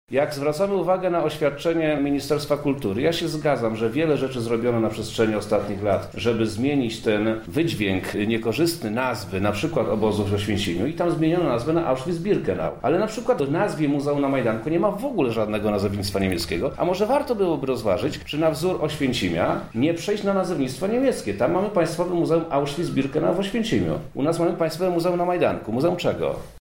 Sprawę komentuje wojewoda lubelski, Przemysław Czarnek.